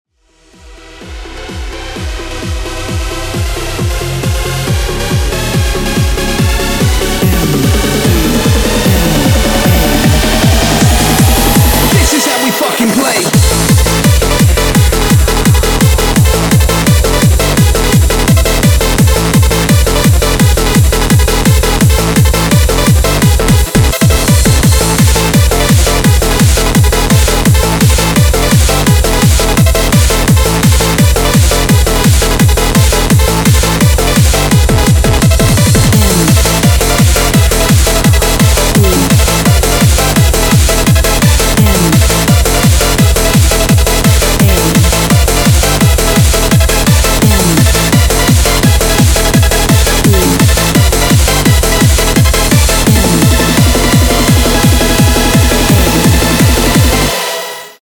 громкие
мощные
hardcore
Electronic
энергичные
быстрые
Bass Boosted
happy hardcore
Очень скоростная электроника